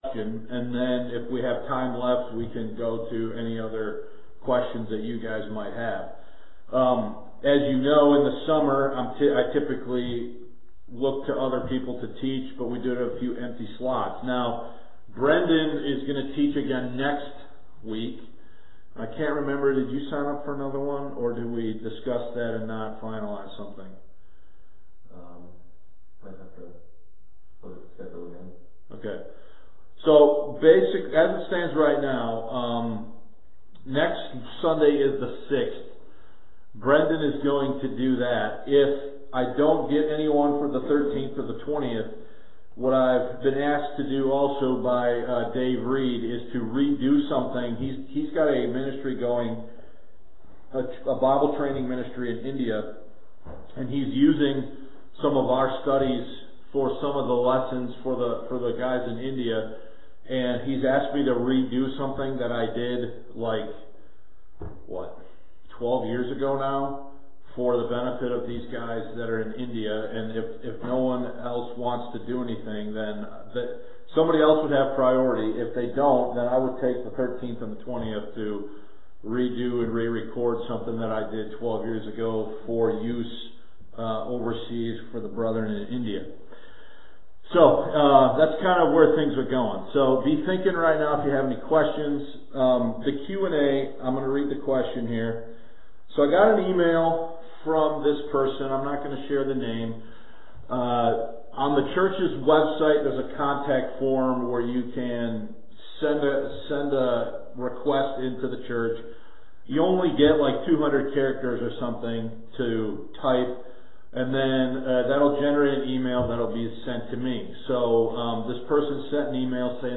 Sunday School Q&A (The Making of the KJB Conference Message Follow Up)